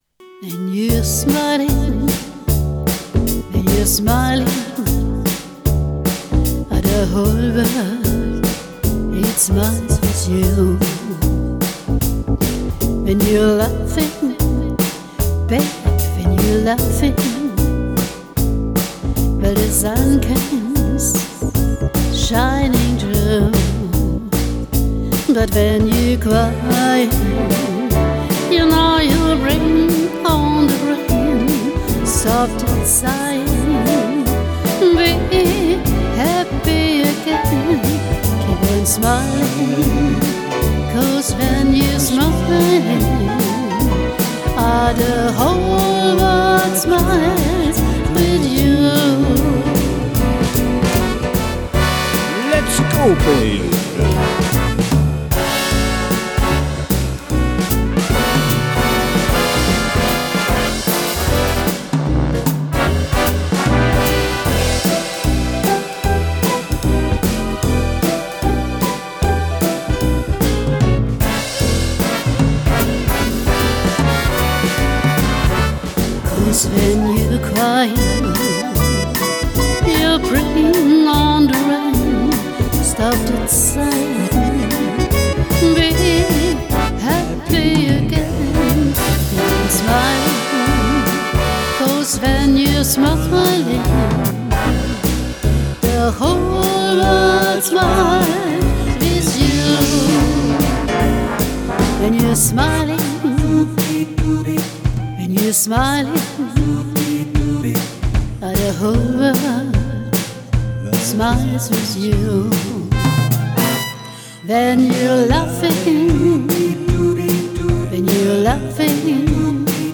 Neue Aufnahmen mit dem Genos einem Keyboard von Yamaha.